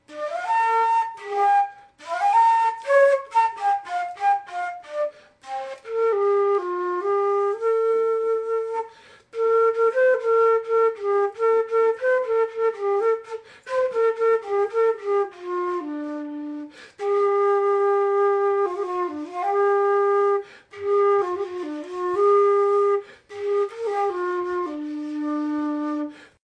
Ten un son especialmente doce, e semella ser de prata pola súa cor.
travesera.mp3